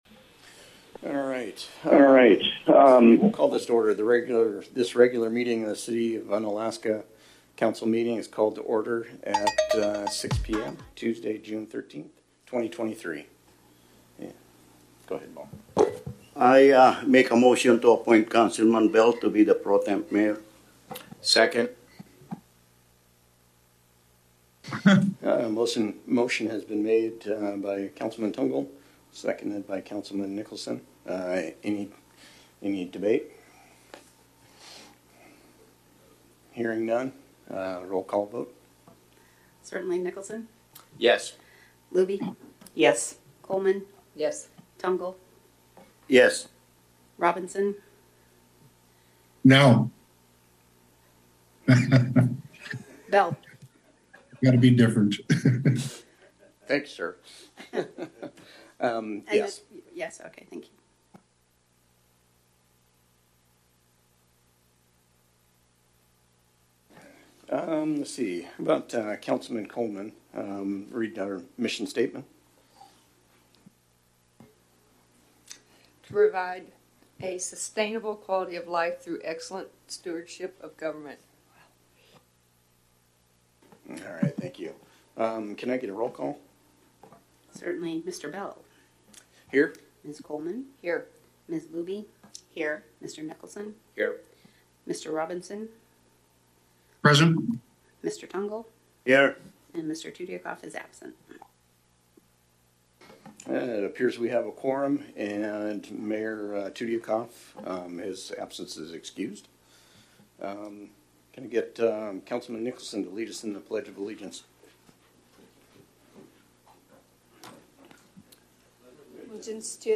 City Council Meeting - June 13, 2023 | City of Unalaska - International Port of Dutch Harbor
In person at City Hall (43 Raven Way)